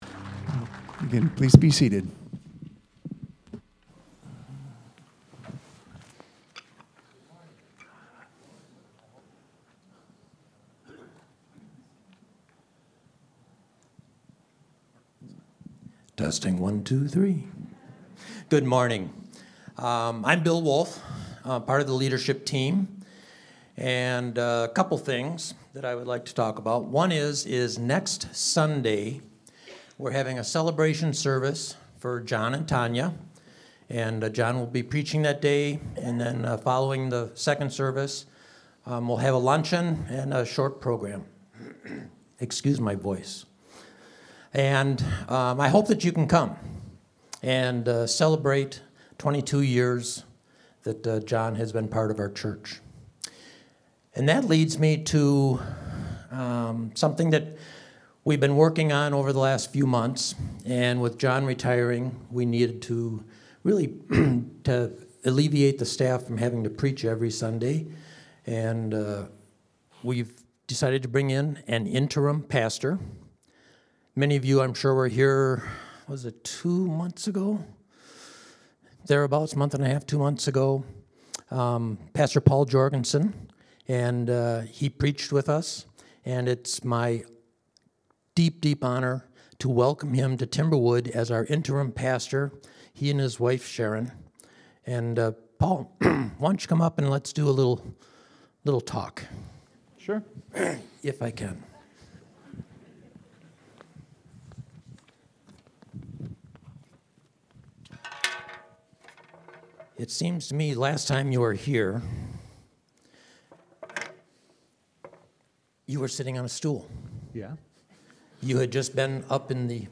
Sunday Sermon: 11-9-25